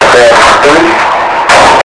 Rubber Bands & Watermelon Explosion Sound Button - Free Download & Play